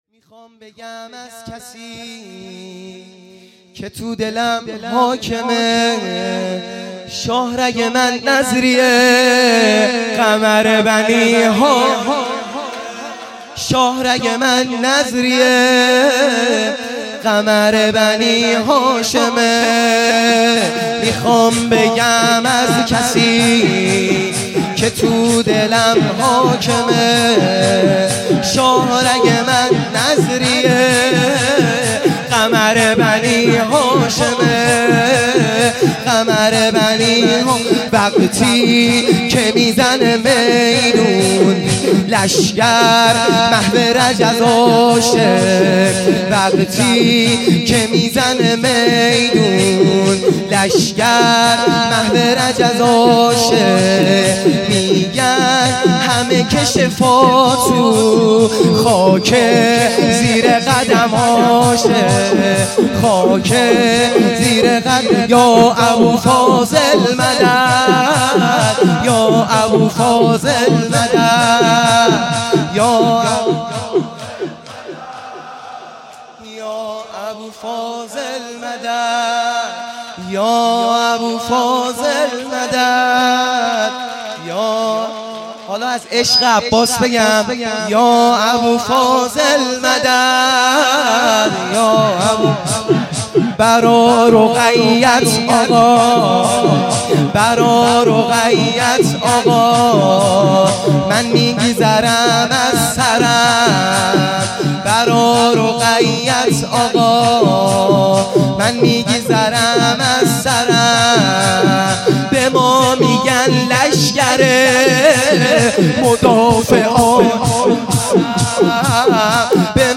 شور | میخوام بگم از کسی
شب نهم محرم الحرام ۱۳۹۶